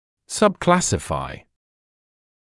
[sʌb’klæsɪfaɪ][саб’клэсифай]относить в подкласс